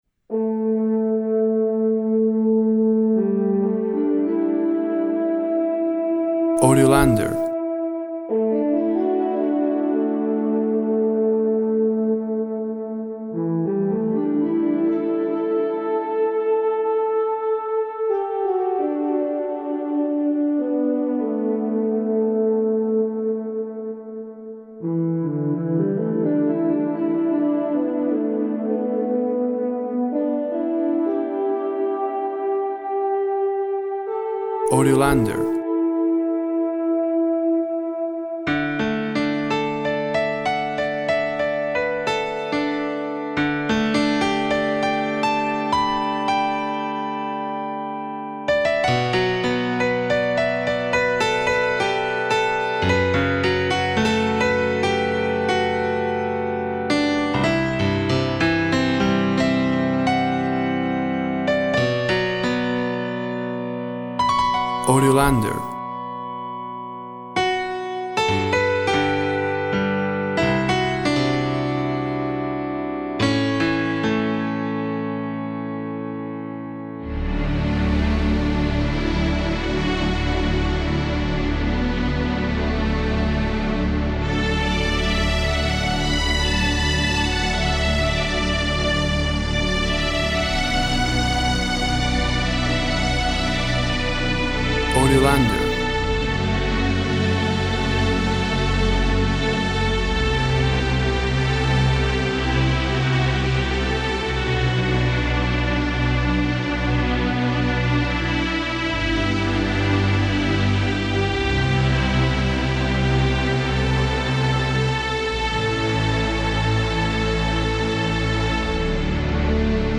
Expansive piano & orchestral tone poem of a panoramic view.
Tempo (BPM) 60